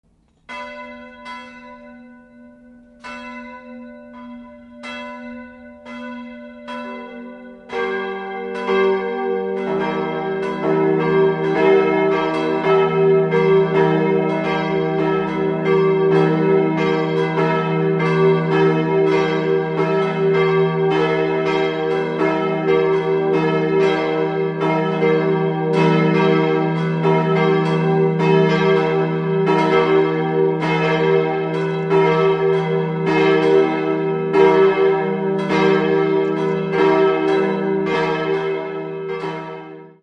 Im Inneren ist der prunkvolle Rokokohochaltar eine Augenweide. 3-stimmiges E-Moll-Geläute: e'-g'-h' Die beiden großen Glocken sind Gussstahlglocken und wurden vom Bochumer Verein im Jahr 1948 gegossen, die kleine h' ist hingegen historisch.